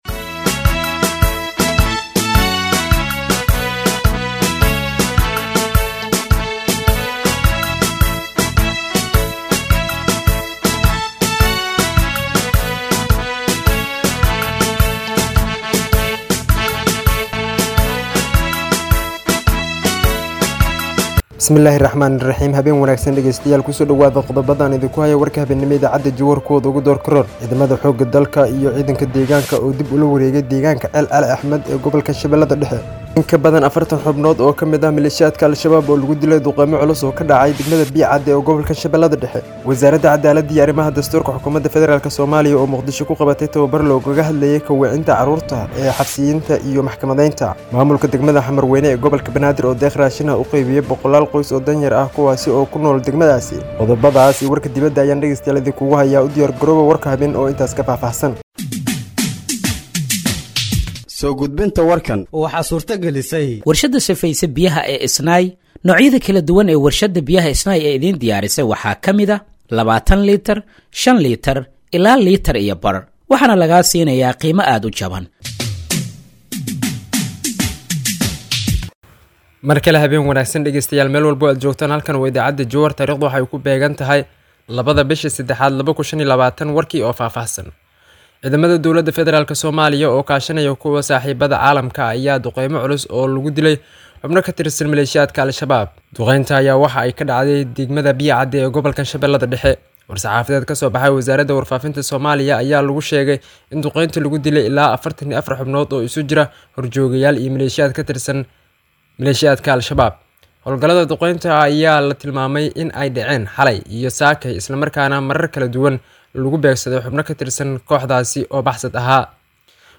Dhageeyso Warka Habeenimo ee Radiojowhar 02/03/2025